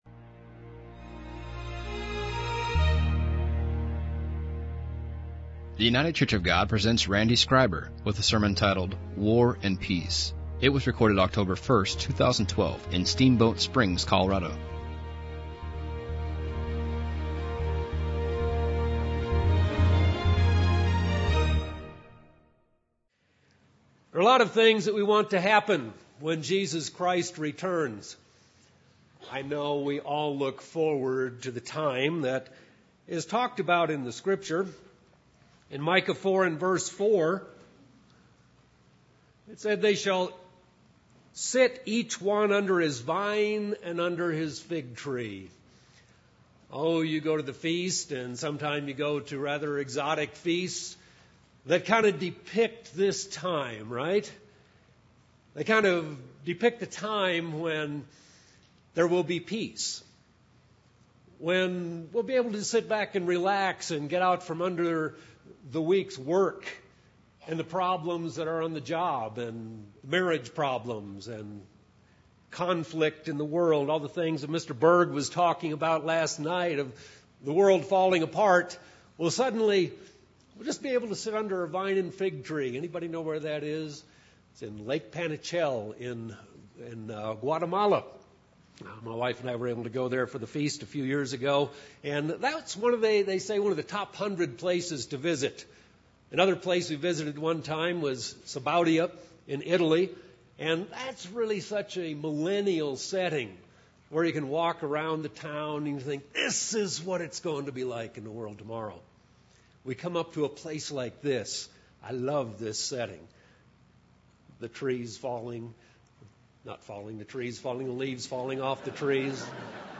This sermon was given at the Steamboat Springs, Colorado 2012 Feast site.